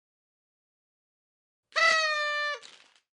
partyHorn.mp3